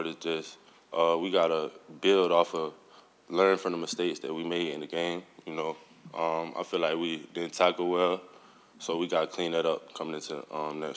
news conference